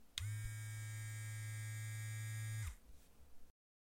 剃刀 " 剃刀 剃刀远
描述：电动剃须机。
Tag: 剃须刀 机械 机器 电动剃刀 剃须机 电动剃须刀 发动机